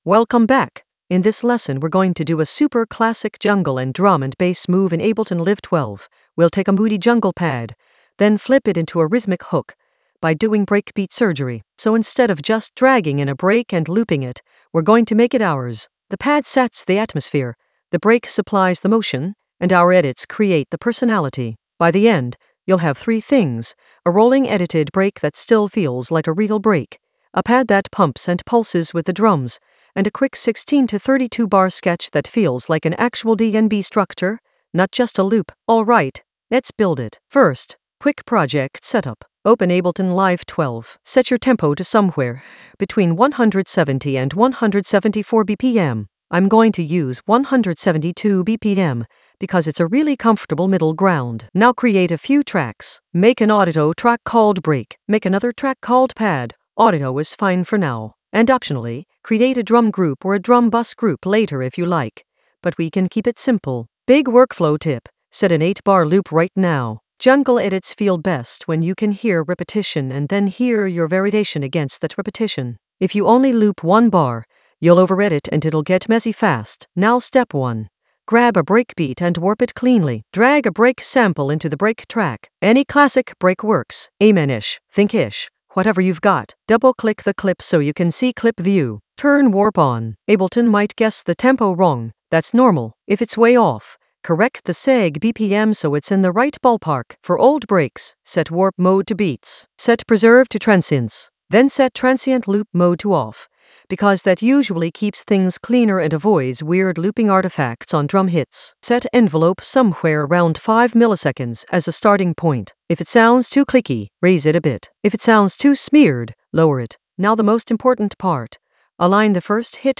Narrated lesson audio
The voice track includes the tutorial plus extra teacher commentary.
An AI-generated beginner Ableton lesson focused on Flip jungle pad with breakbeat surgery in Ableton Live 12 in the Drums area of drum and bass production.